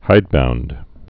(hīdbound)